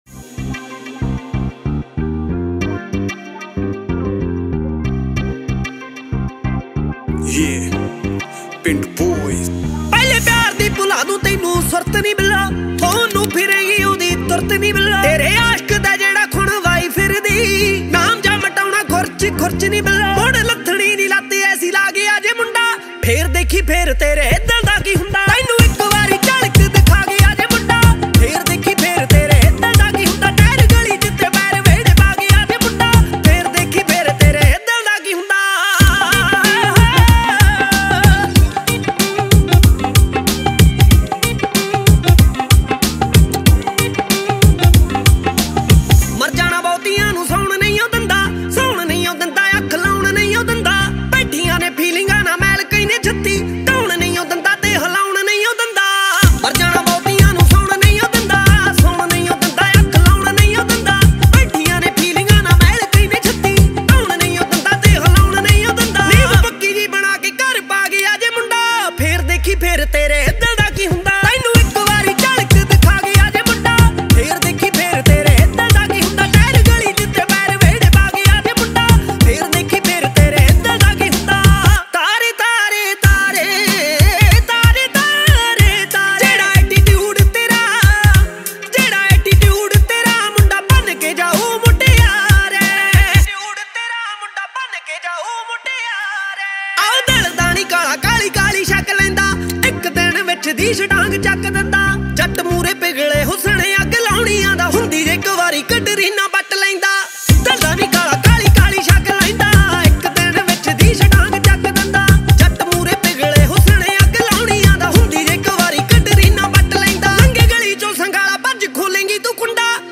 Category: New Punjabi Mp3 Songs